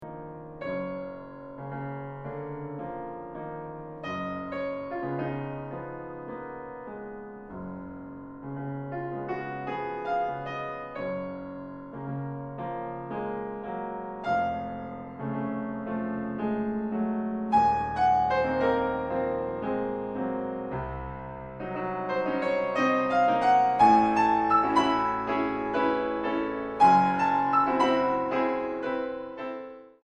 Tranquilo 2.34